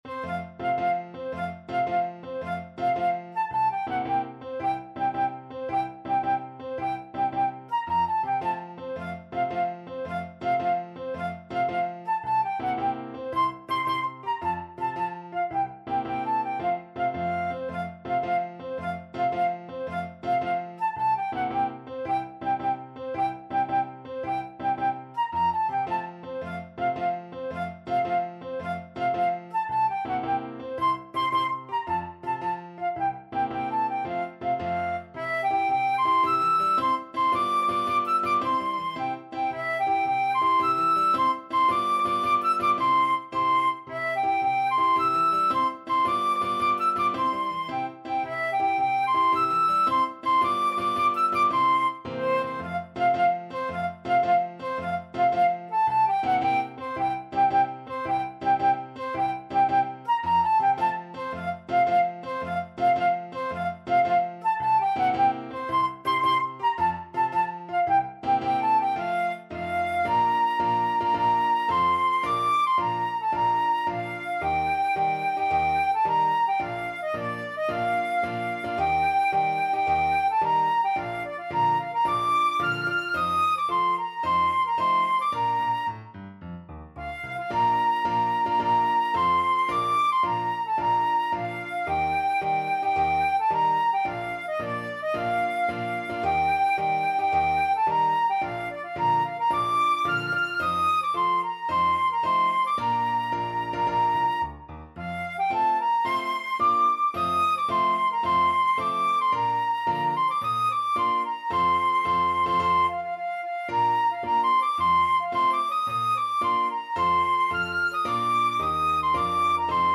Flute
F major (Sounding Pitch) (View more F major Music for Flute )
6/8 (View more 6/8 Music)
Classical (View more Classical Flute Music)
piefke_koniggratzer_marsch_FL.mp3